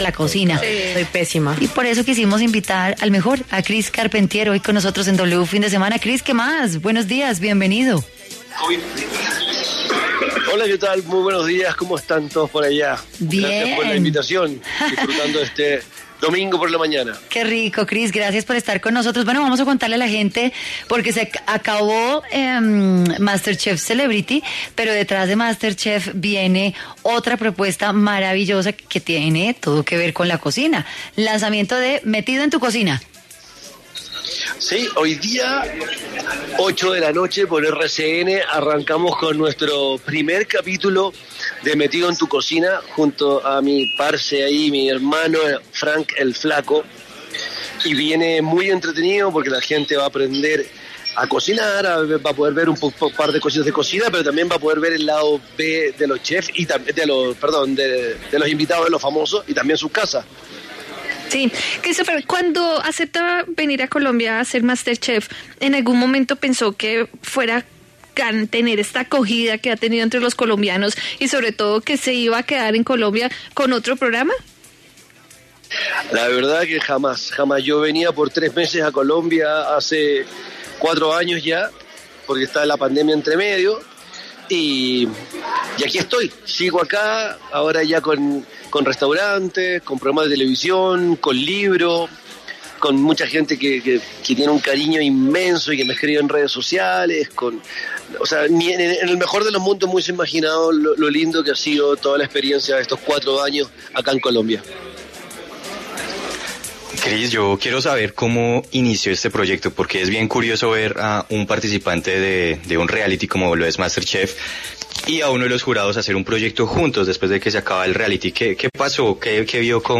En W Fin de Semana dialogamos con el reconocido Jurado de ‘Masterchef Celebrity’, quien ahora regresa al Canal RCN con un novedoso formato.